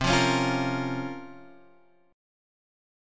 Bbm/C chord